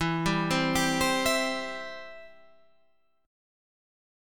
Em#5 chord